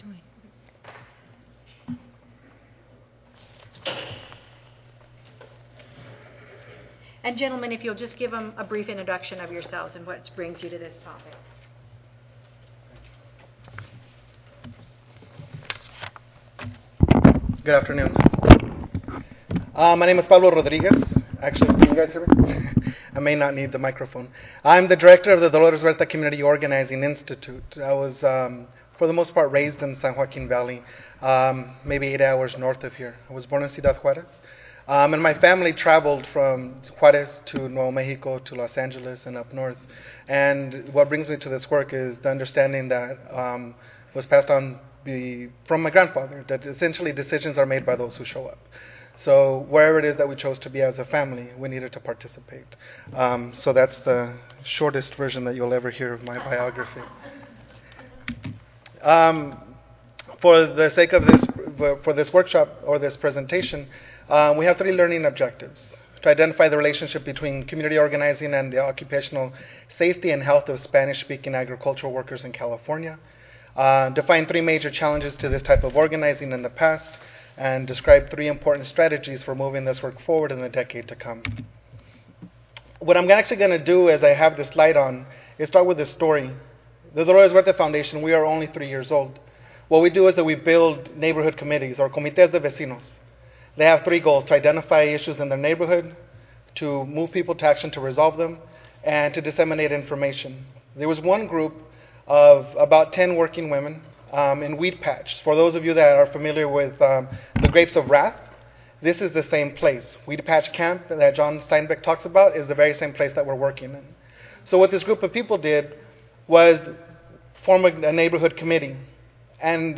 4297.0 Occupational Health Disparities Institute: Farmworkers: Rights, Justice and Health Tuesday, October 28, 2008: 2:30 PM Oral This session presents farmworkers' experiences from a variety of perspectives, focusing on helping farmworkers achieve their rights - as workers and as human beings. This set of presentations includes discussions of the reality of unequal worker protections for farmworkers, stress and quality of life issues that are related to being a farmworker, border issues, and also discussions of approaches to achieving better conditions for farmworkers - mobilizing workers themselves and considerations on training.